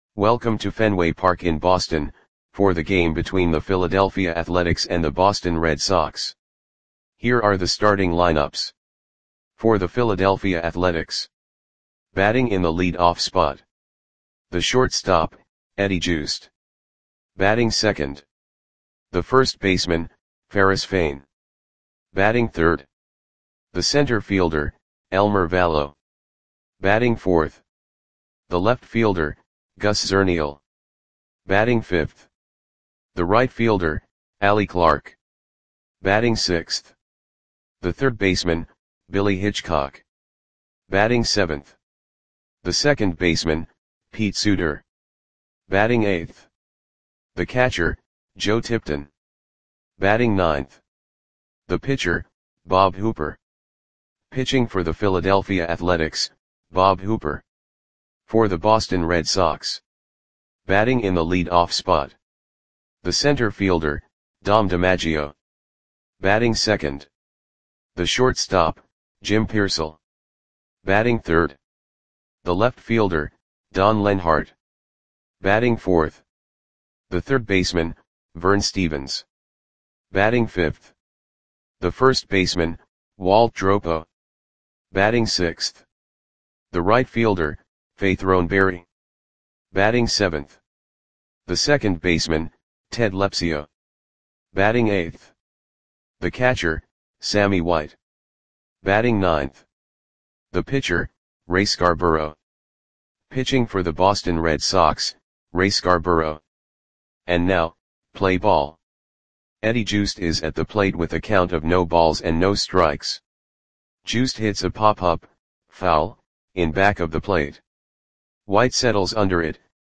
Click the button below to listen to the audio play-by-play.